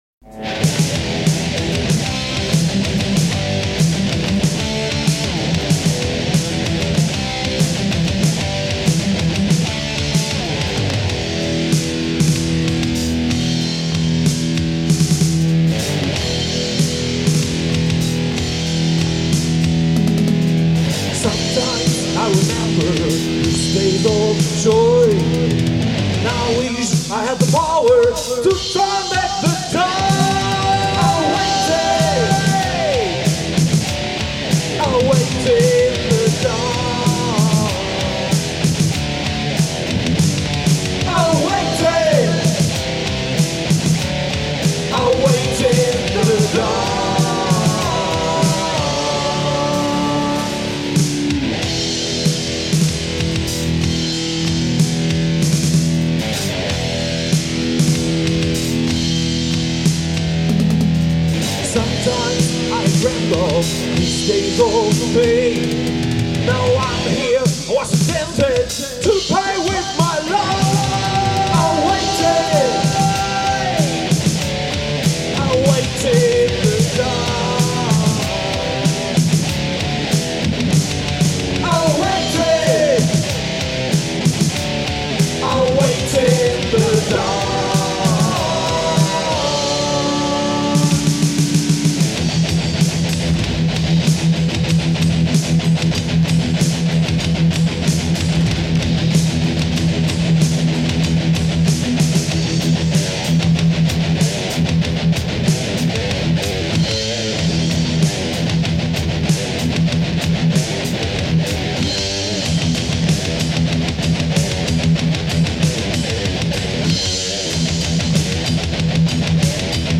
Eigenproduktion nur für Promotionzwecke
Gitarre, Keyboards
Schlagzeug